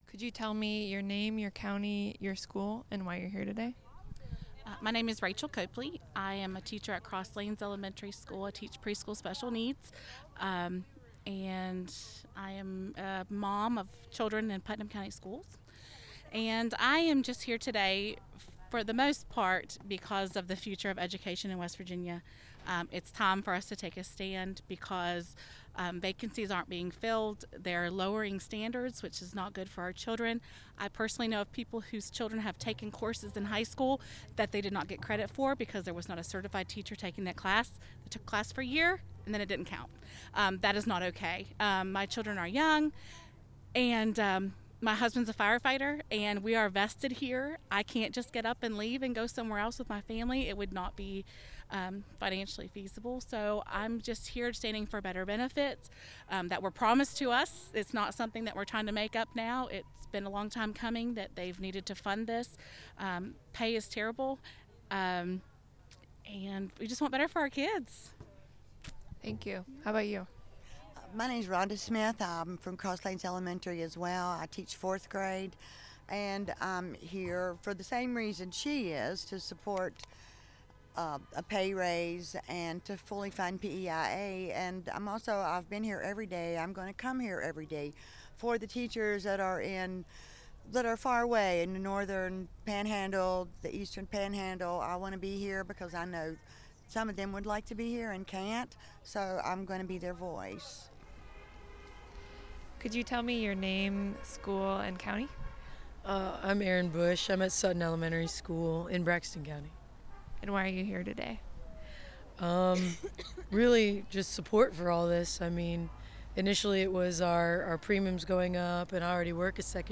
Vox pop interviews with West Virginia teachers, students, state employees, and union members at the West Virginia Teachers' Strike rally at the state capitol